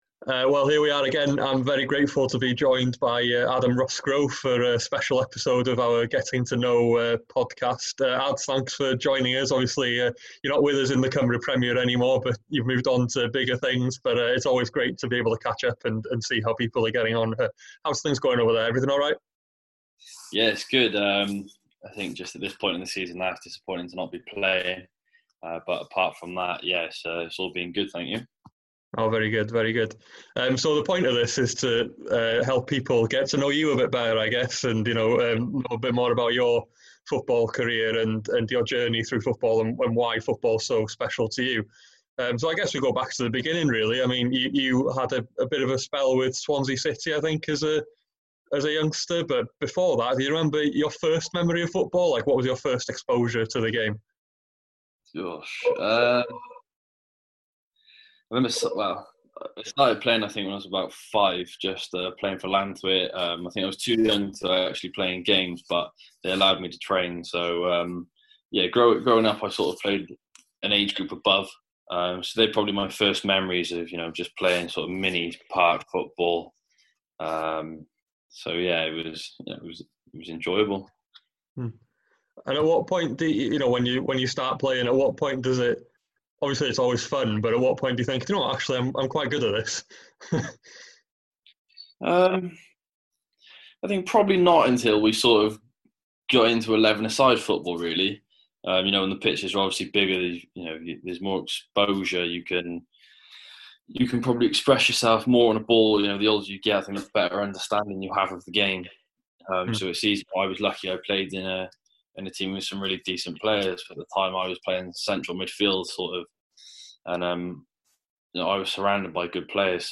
We're bringing you another one of our Getting to Know special episodes this week as we chat with one of the JD Cymru Premier's most recent exports.